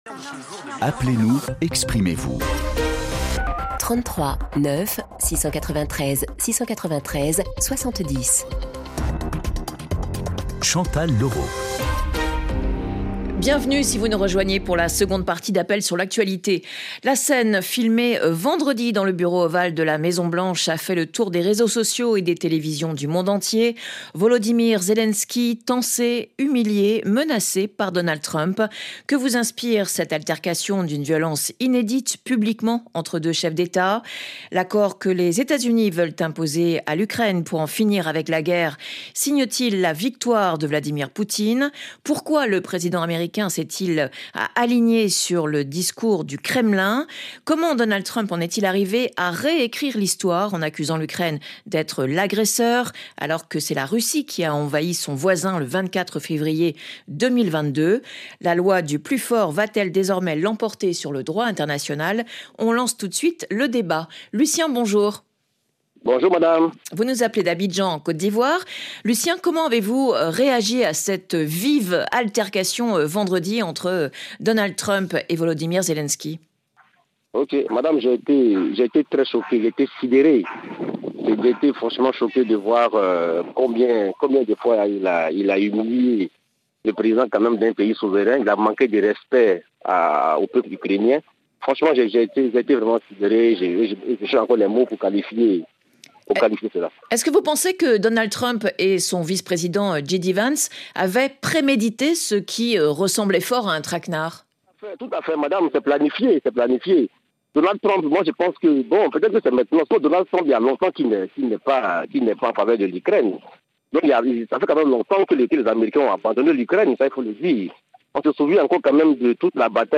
Le rendez-vous interactif des auditeurs de RFI.